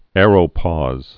(ârō-pôz)